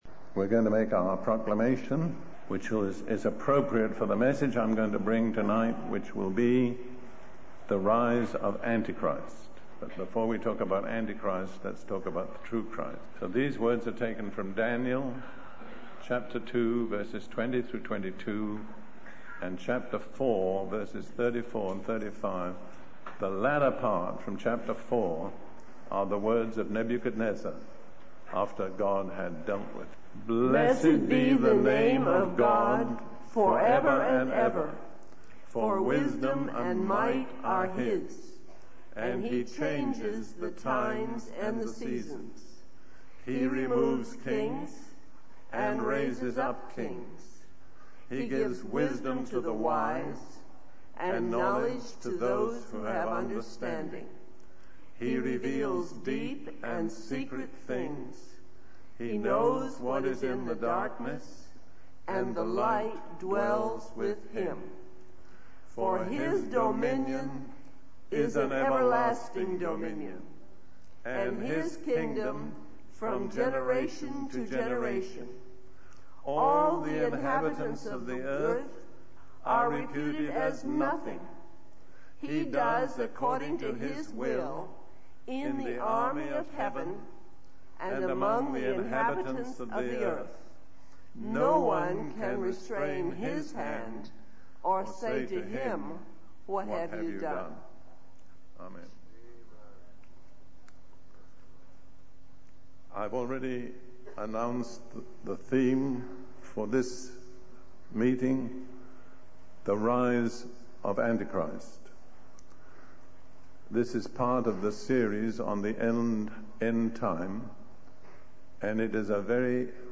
In this sermon, the preacher discusses the concept of receiving a mark on the right hand or forehead, as mentioned in Revelation 13:16.